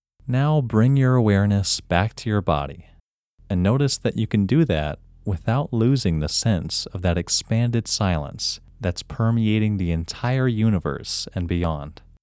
WHOLENESS English Male 16
WHOLENESS-English-Male-16.mp3